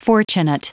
Prononciation du mot fortunate en anglais (fichier audio)
Prononciation du mot : fortunate